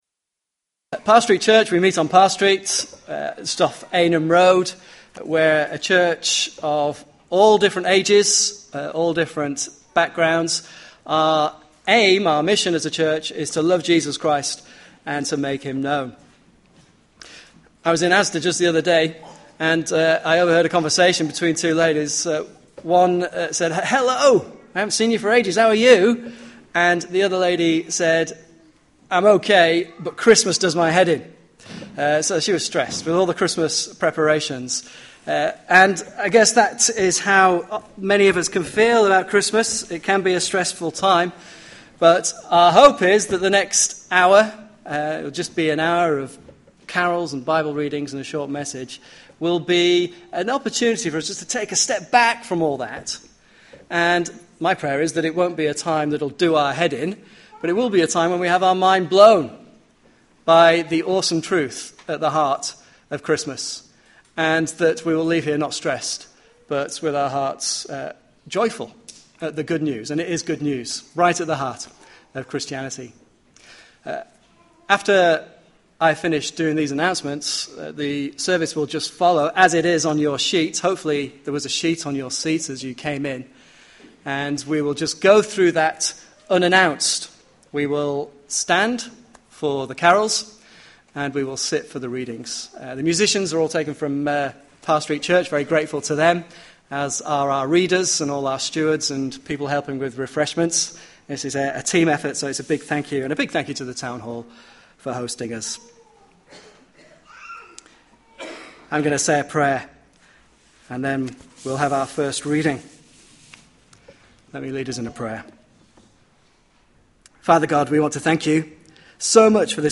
Carol Service in Kendal Town Hall – Parr Street Church Audio Centre
Service Type: Evening Service